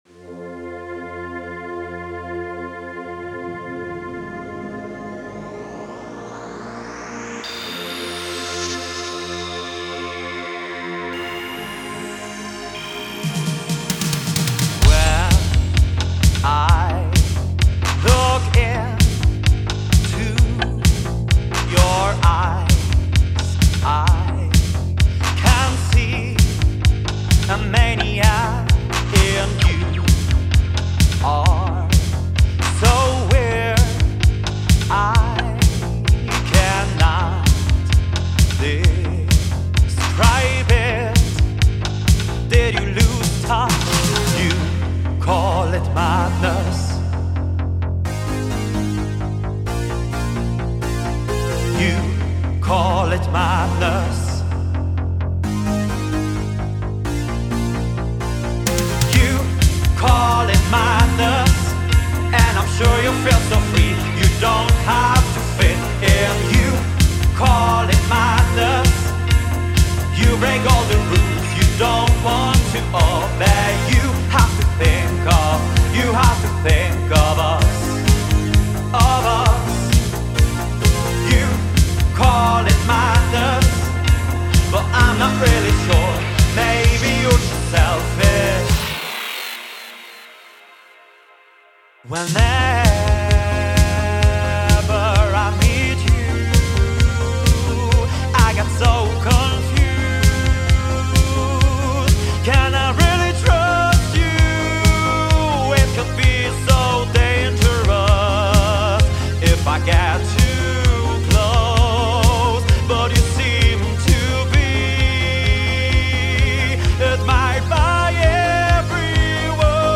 80's Style Song "Madness" für Mixing und/oder Mastering
Hier mal eine Idee (habe es sicherheitshalber etwas lauter gemacht weil sonst der nächste noch lauter usw.)